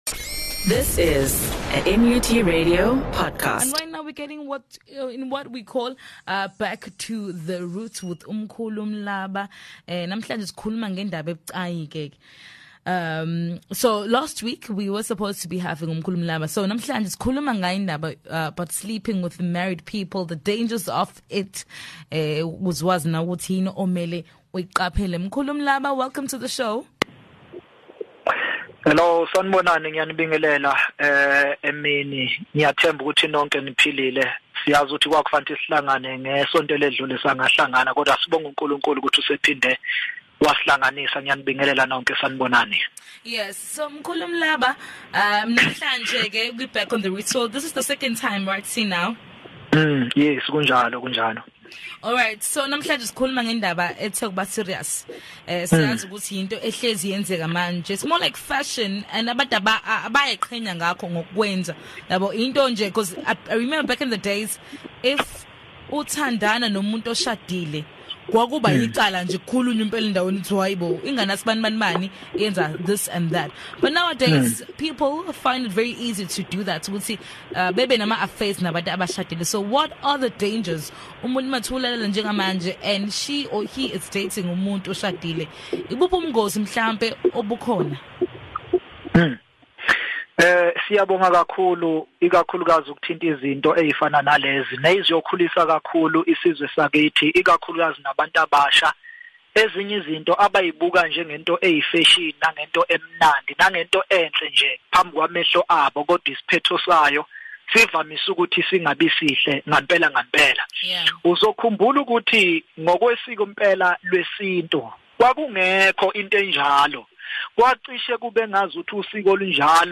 The Hlanganani Midday show had a conversation